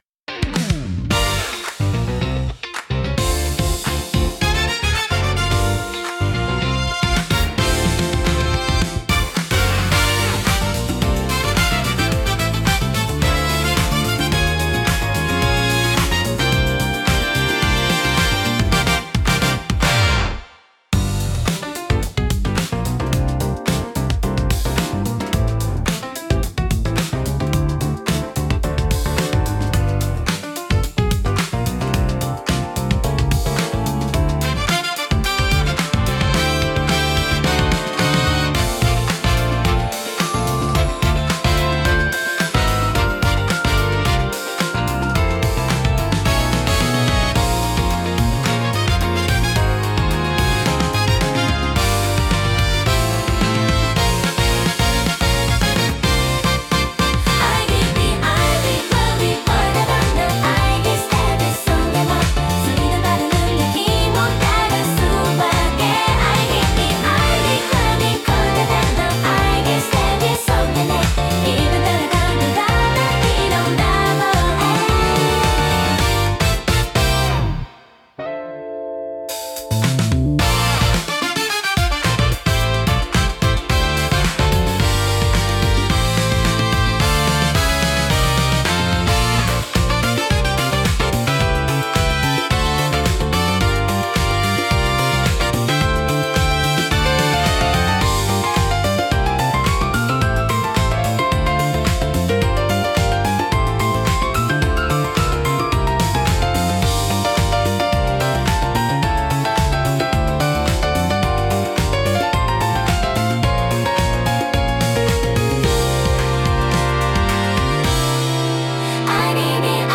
聴く人に洗練された印象を与えつつ、リラックスと活気のバランスを巧みに表現します。